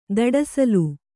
♪ daḍasalu